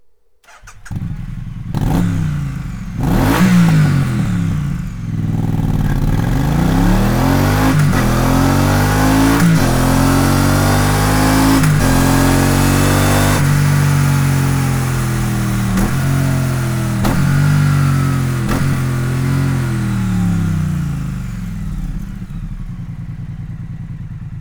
Sound Serienauspuff Triumph Street Twin